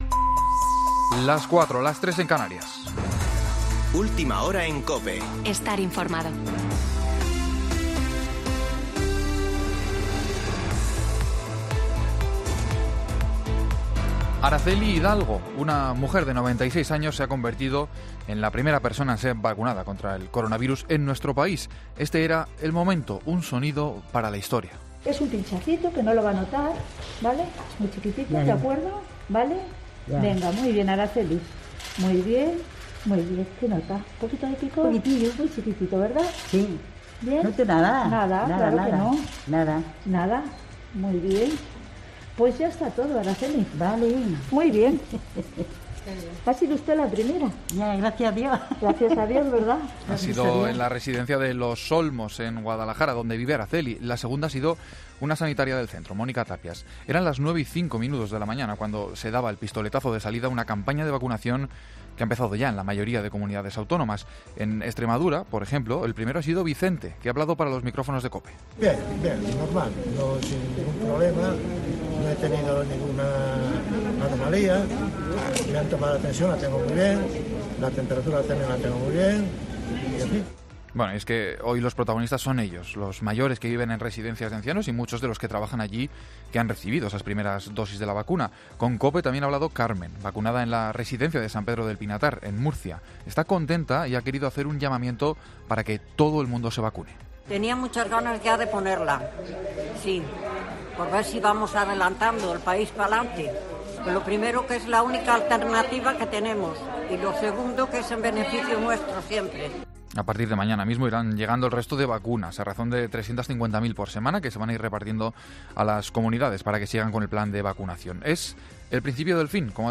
Boletín de noticias de COPE del 27 de diciembre de 2020 a las 16.00 horas